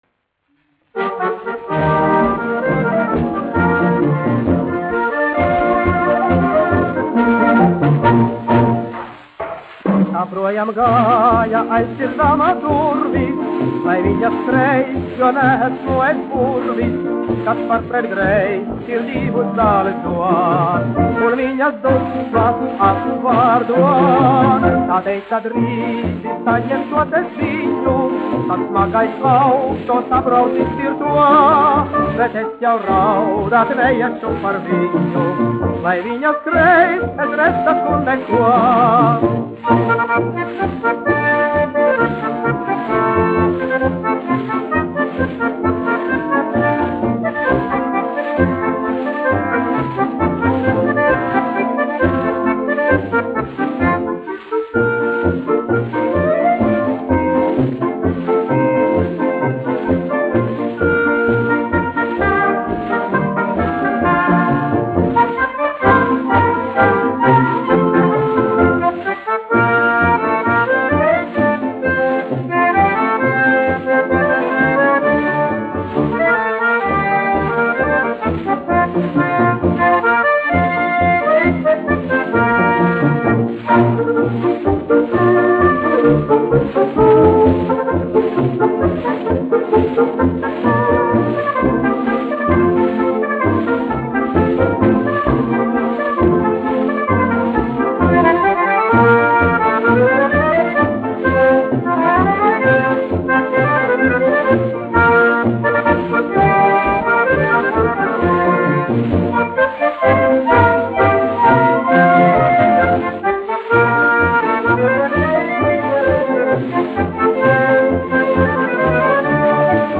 1 skpl. : analogs, 78 apgr/min, mono ; 25 cm
Fokstroti
Populārā mūzika
Skaņuplate